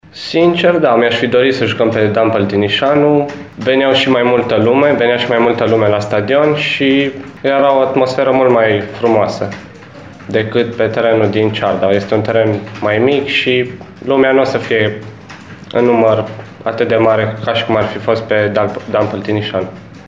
La conferința de presă de astăzi, alb-violeții au declarat că și-ar fi dorit ca meciul să se desfășoare pe stadionul „Dan Păltinișanu”, acolo unde numărul spectatorilor ar fi fost cu siguranță mai mare.